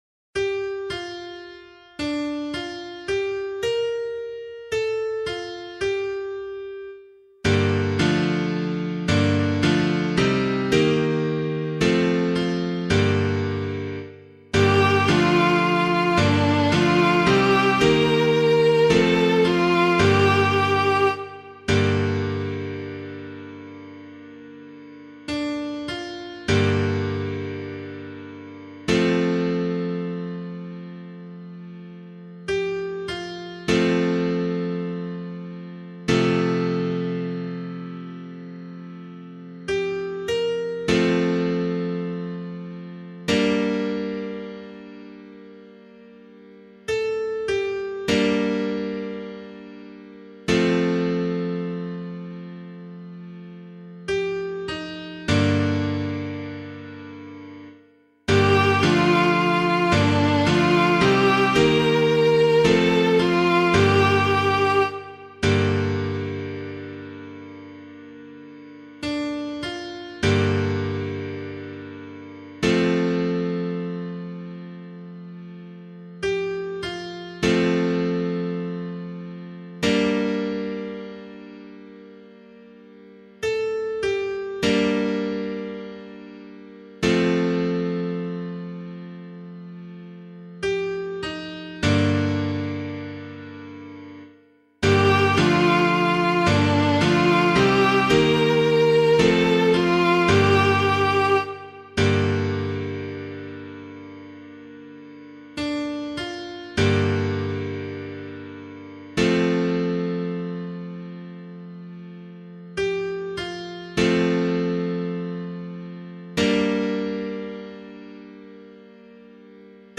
021 Good Friday Psalm [LiturgyShare 3 - Oz] - piano.mp3